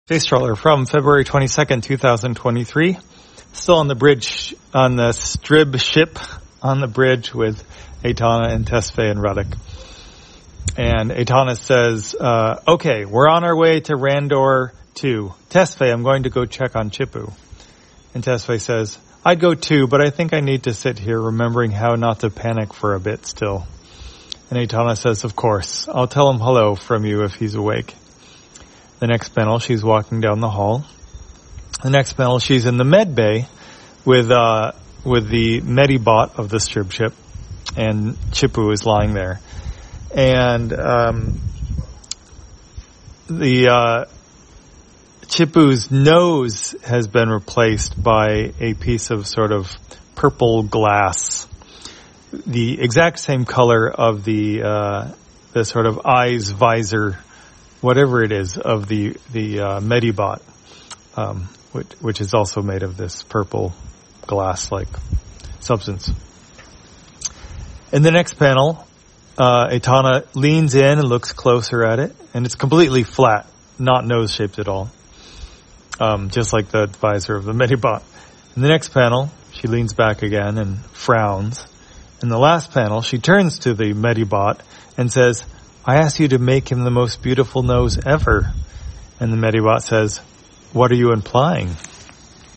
Spacetrawler, audio version For the blind or visually impaired, February 22, 2023.